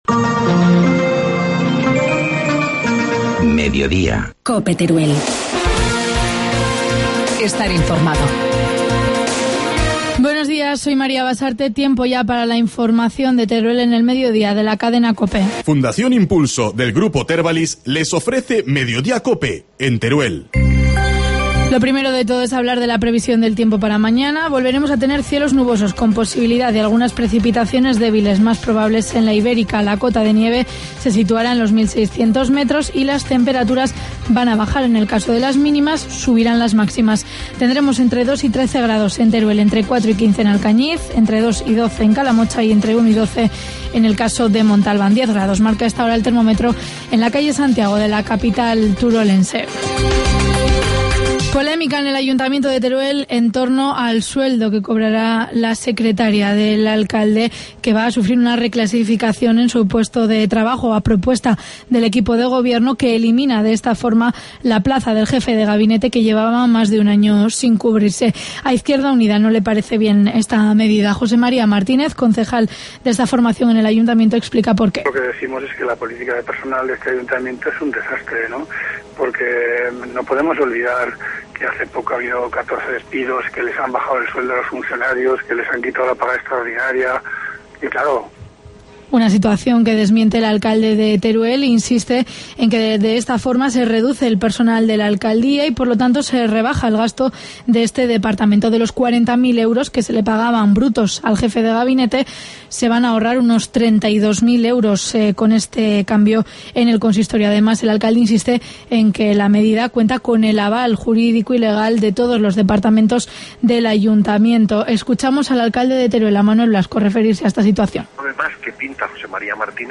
Informativo mediodía, martes 19 de febrero